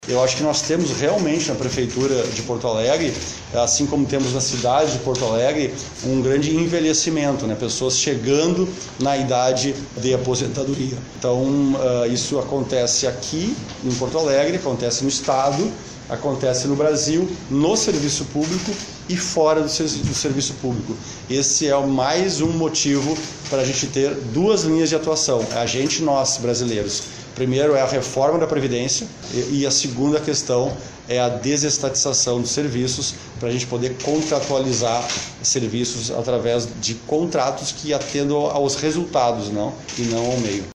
Em coletiva de imprensa antes de palestrar a empresários na Federação das Entidades Empresariais do RS (Federasul) nesta quarta-feira, o prefeito de Porto Alegre, Nelson Marchezan Jr, comemorou a aprovação do projeto que altera estatuto dos servidore e disse que o diálogo com o Sindicato dos Municipários da Capital (Simpa) está encerrado.